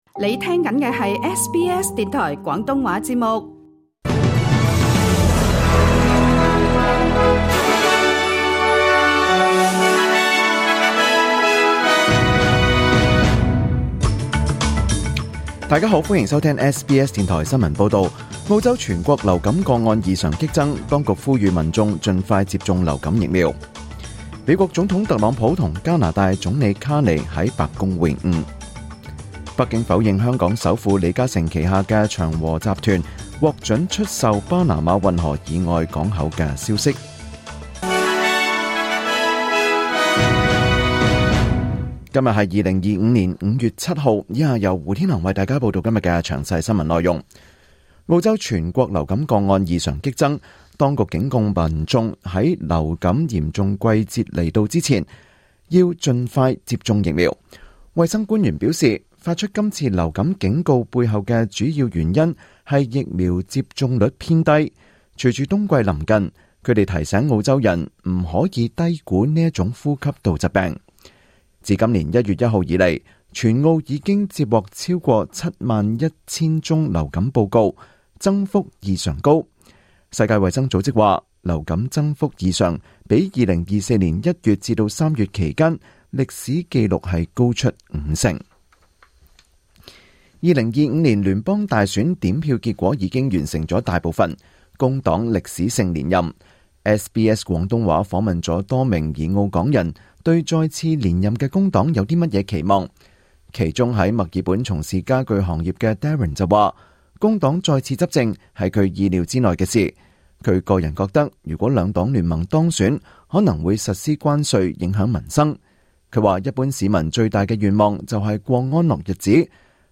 2025 年 5 月 7 日 SBS 廣東話節目詳盡早晨新聞報道。